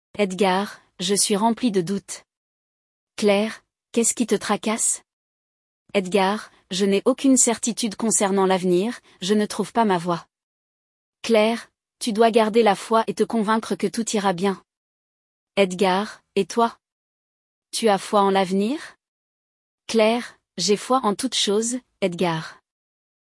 Nesse episódio dois amigos conversam sobre a importância de acreditar que tudo vai dar certo.